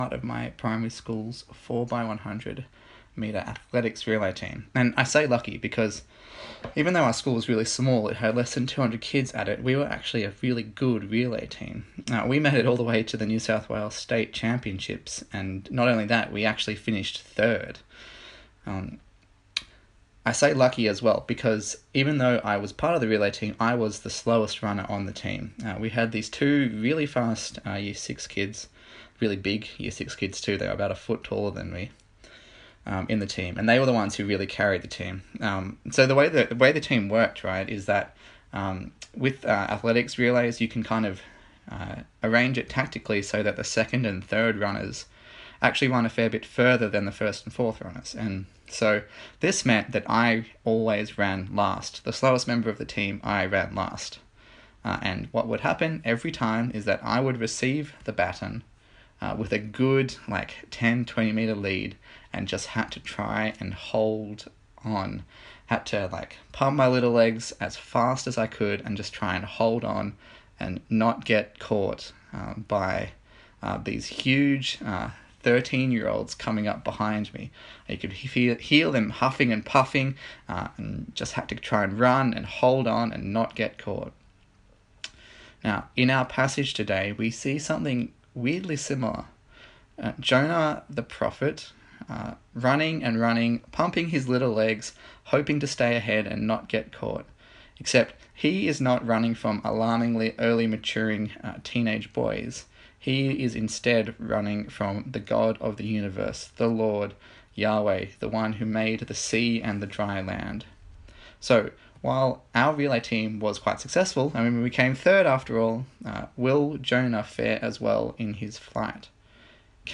Service Type: Sunday Morning A sermon in the series on the book of Jonah